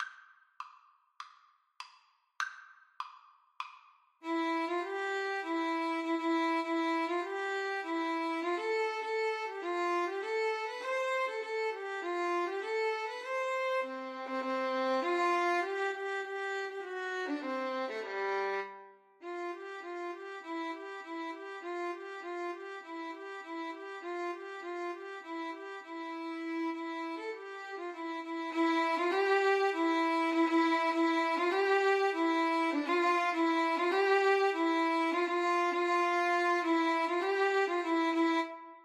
Free Sheet music for Violin Duet
4/4 (View more 4/4 Music)
Allegro Moderato (View more music marked Allegro)
C major (Sounding Pitch) (View more C major Music for Violin Duet )
Traditional (View more Traditional Violin Duet Music)